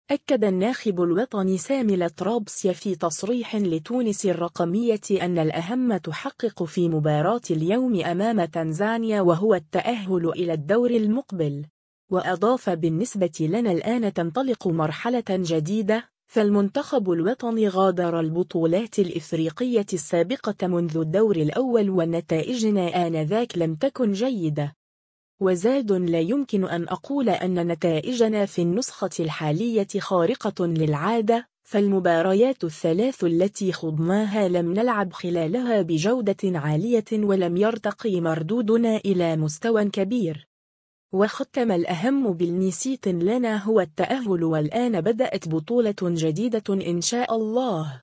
أكّد الناخب الوطني سامي الطرابسي في تصريح لتونس الرقمية أنّ الأهم تحقق في مباراة اليوم أمام تنزانيا و هو التأهّل إلى الدّور المقبل.